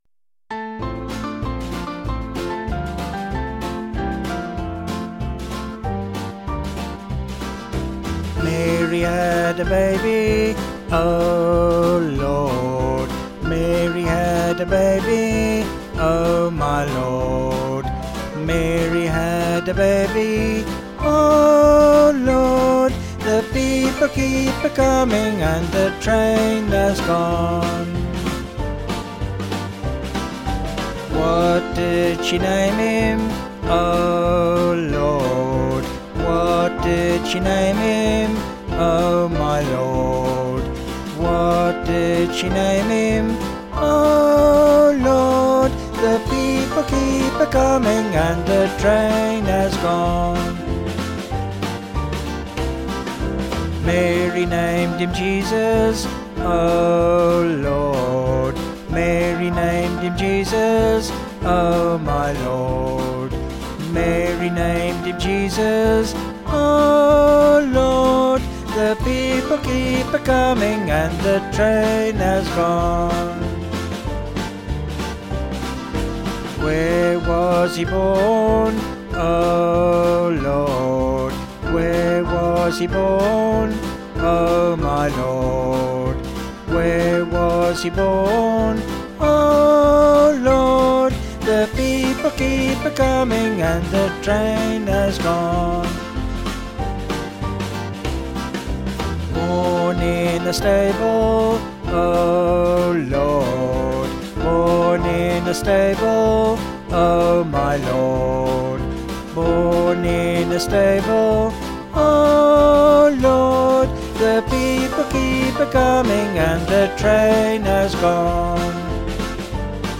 Vocals and Band   703kb